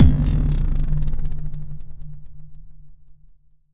Synth Impact 03.wav